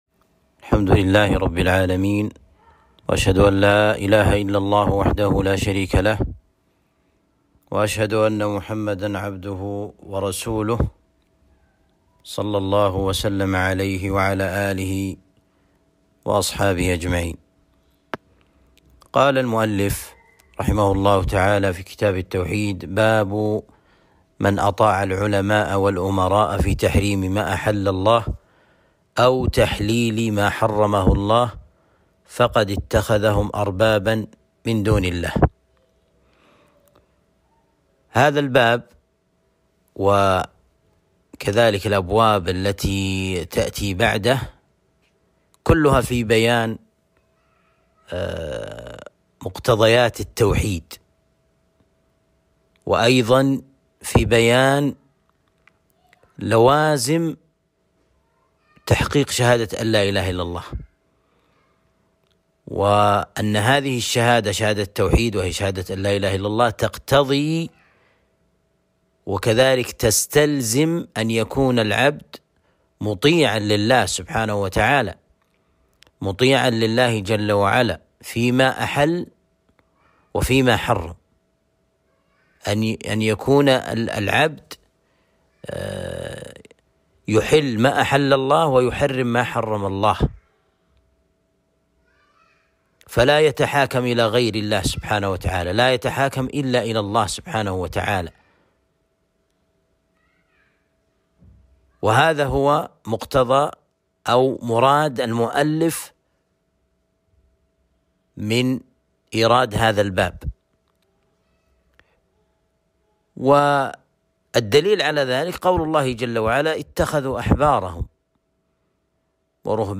درس شرح كتاب التوحيد (38)